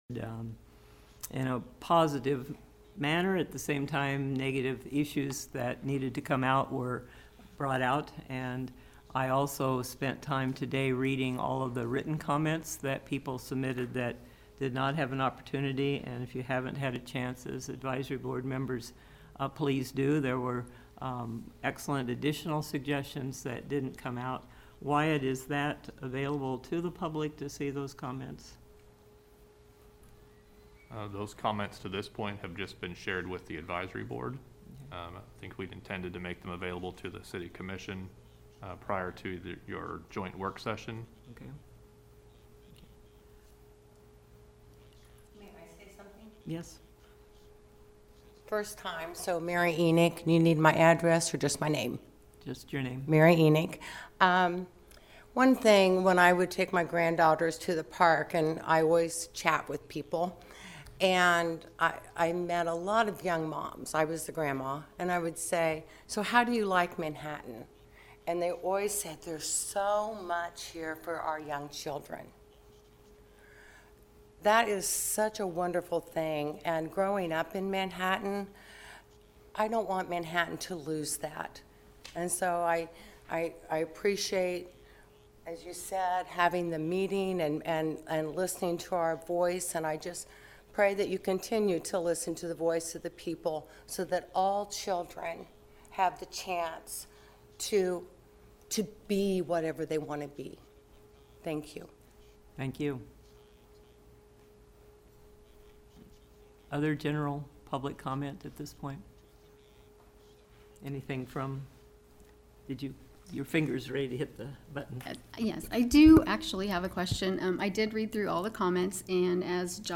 Deputy City Manager Jason Hilgers addressed questions related to the financial situation of the Manhattan Parks & Recreation Department Monday at the monthly Parks & Recreation Advisory Board meeting.